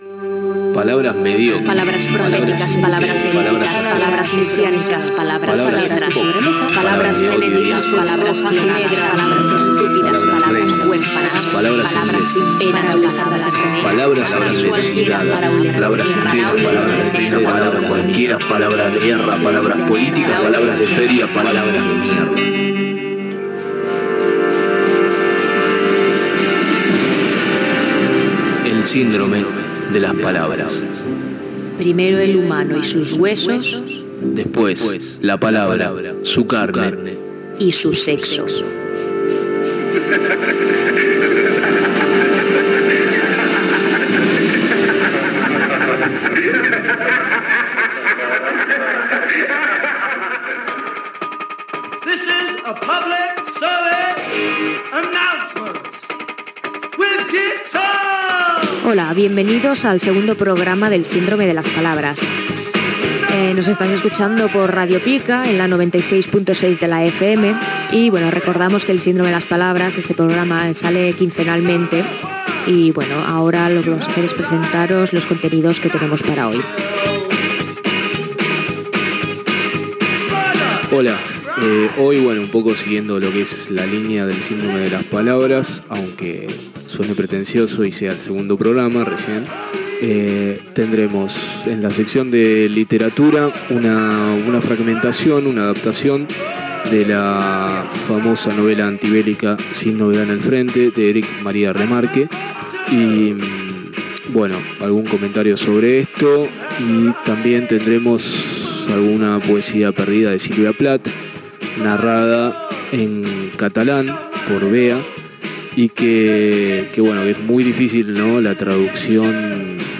Careta del programa, presentació del segon programaamb els continguts, indicatiu i tema musical
FM